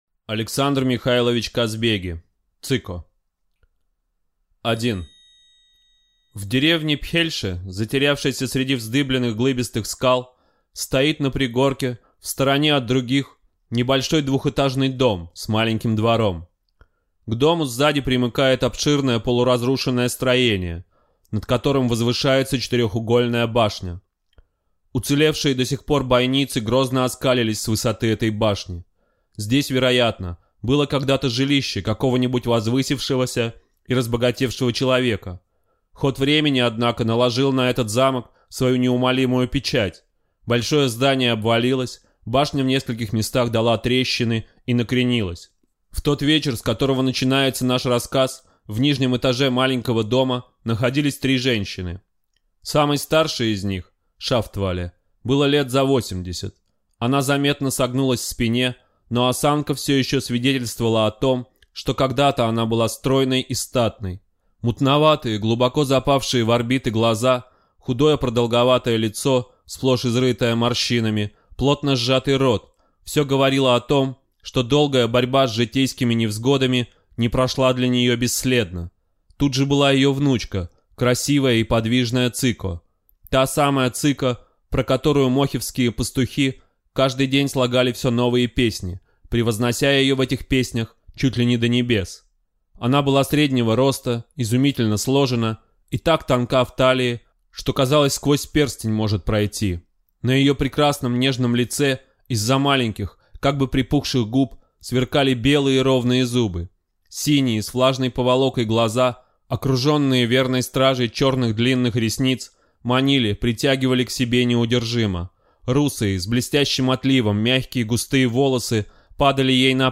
Аудиокнига Цико | Библиотека аудиокниг